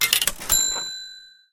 Pixel Gun 3D Purchase Sound